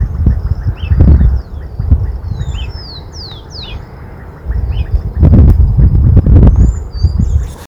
Bran-colored Flycatcher (Myiophobus fasciatus)
Location or protected area: Concordia
Condition: Wild
Certainty: Recorded vocal